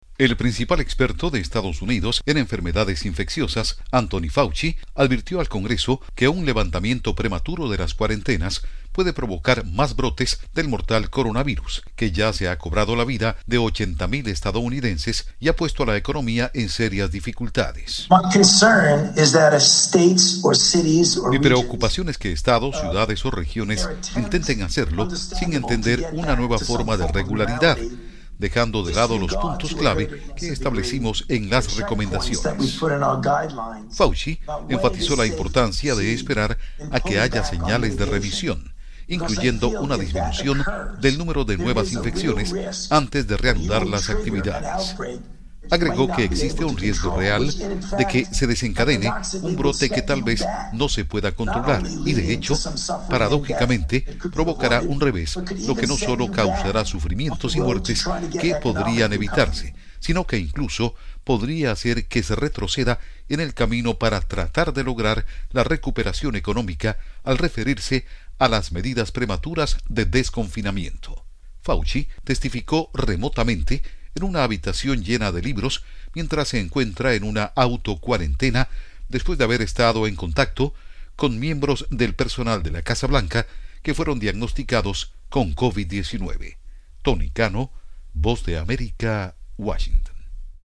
Anthony Fauci ofrece declaraciones al Senado sobre coronavirus